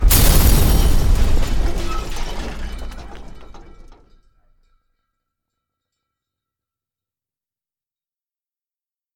var crash; // Variable für den Zusammenstoß-Klang deklarieren
crash.mp3